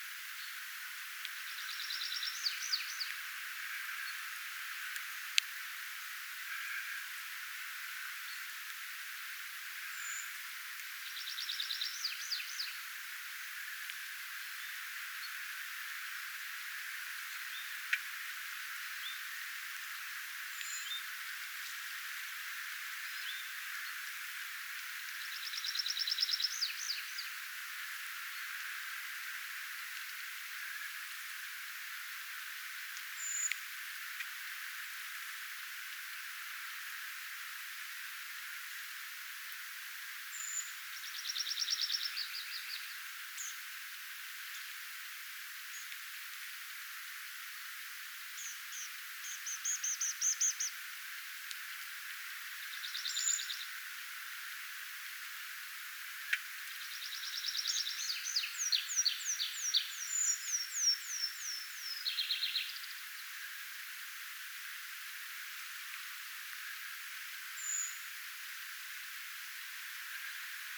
kevään ensimmäistä metsäkirvisen laulua
Myöskin metsäkirvisen lentoääntä
sekä puukiipijä äänelee harvakseltaan
kevaan_ensimmainen_metsakirvisen_laulu_myos_lentoaantelya_koko_ajan_silloin_talloin_puukiipijan_aani.mp3